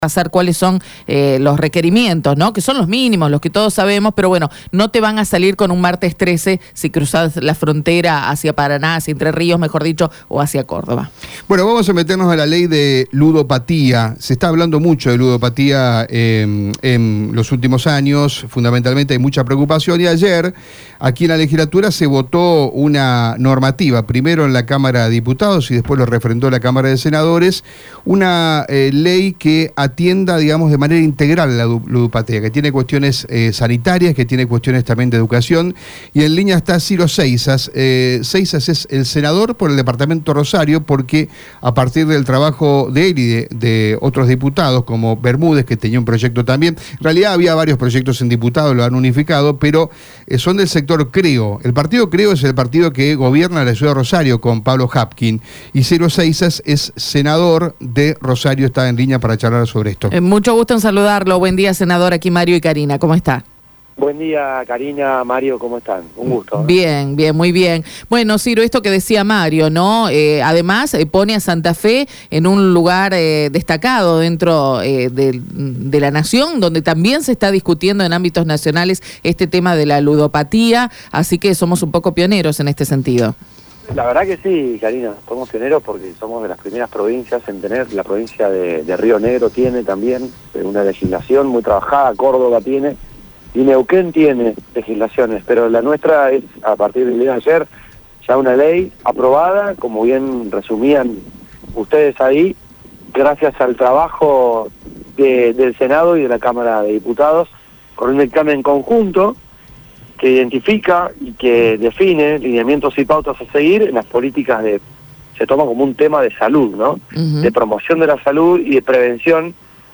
Radio EME se comunicó con Ciro Seisas, Senador Provincial por Rosario por el partido Creo luego de que La Legislatura provincial convirtió en ley, por unanimidad, el marco regulatorio para prevenir el consumo problemático de la ludopatía y el juego de apuestas online.
Escucha la palabra de Ciro Seisas en Radio EME: